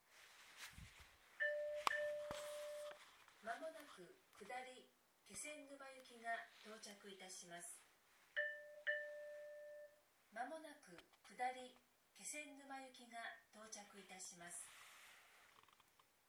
この駅では接近放送が設置されています。
接近放送普通　気仙沼行き接近放送です。